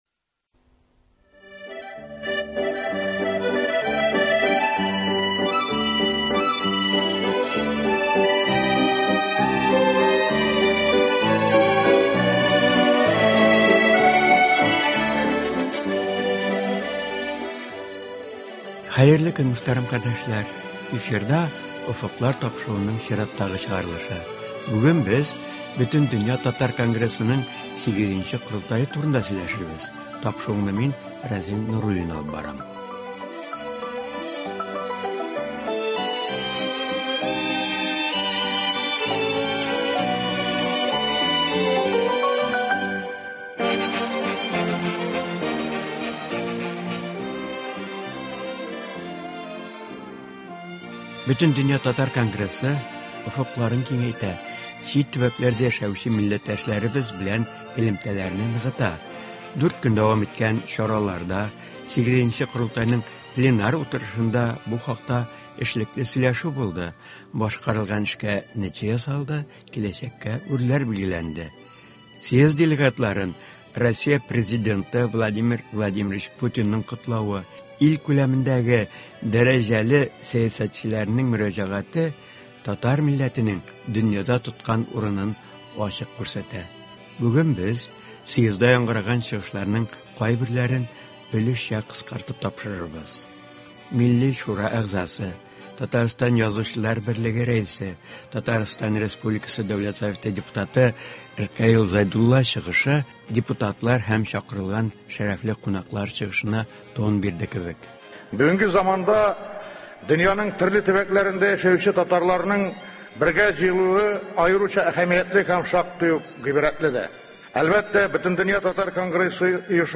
Июль ахырында — август башында Казанда Бөтендөнья татар конгрессының VIII нче корылтае узды. Тапшыру корылтай нәтиҗәләренә багышлана, чит төбәкләрдә яшәүче милләттәшләребез белән әңгәмәләр бирелә.